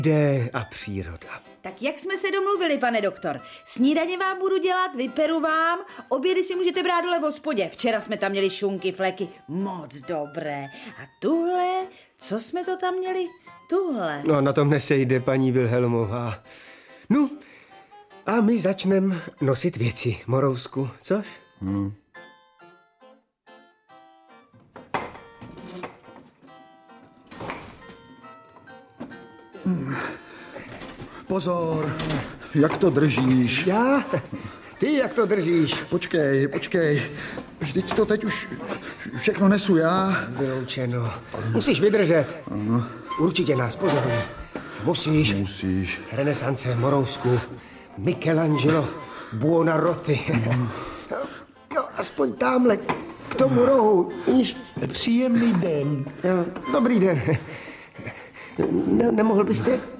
Audiobook
Read: Naďa Konvalinková